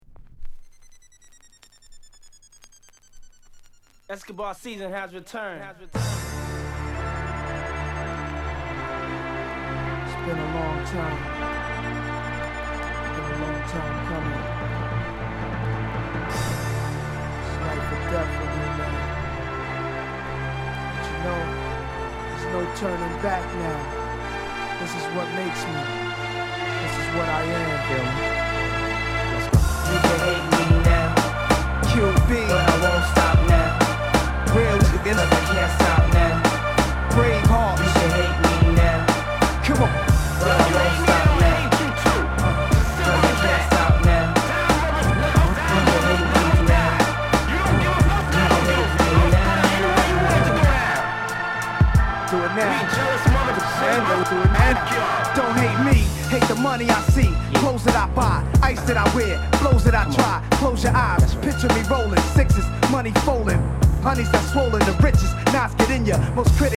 SOUND CONDITION A SIDE EX-